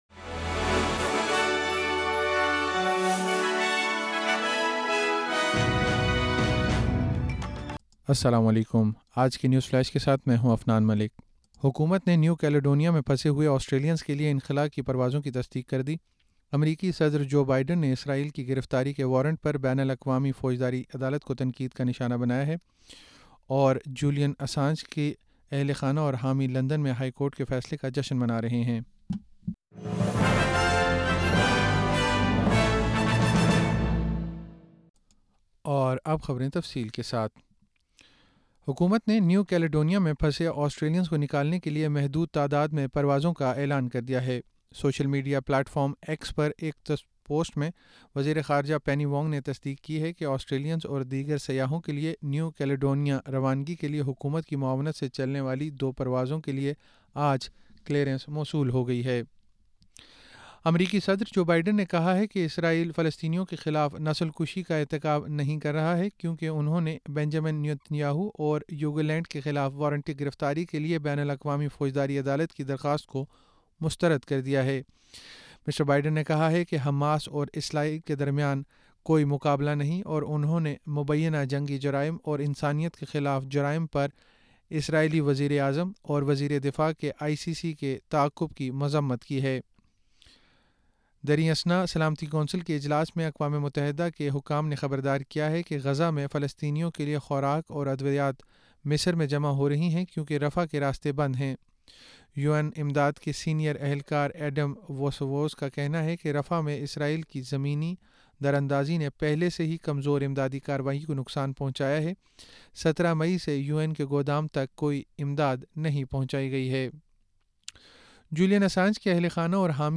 نیوز فلیش 21 مئی 2024: حکومت کی نیو کیلیڈونیا میں پھنسے آسٹریلینز کے انخلا کی پروازوں کی تصدیق